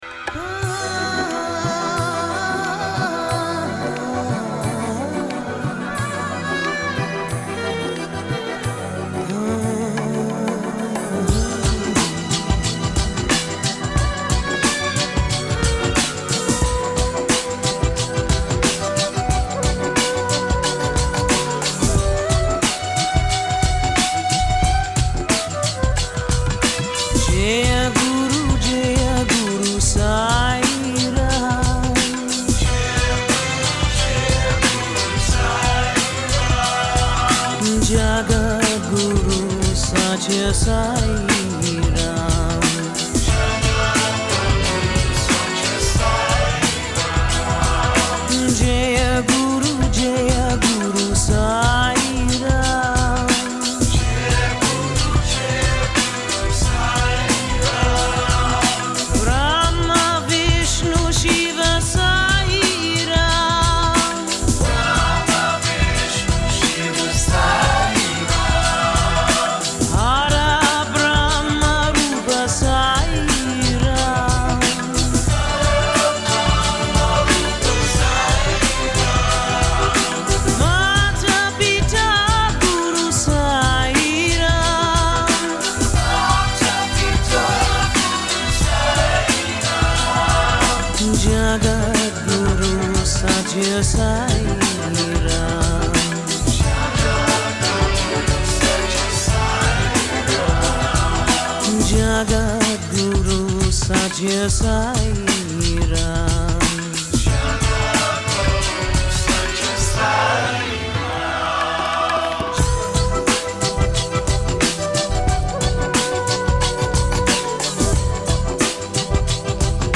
Индийские бажданы в шедевральной аранжировке.
Стиль: Ethnic New Age UK / USA